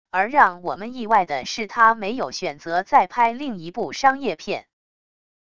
而让我们意外的是他没有选择再拍另一部商业片wav音频生成系统WAV Audio Player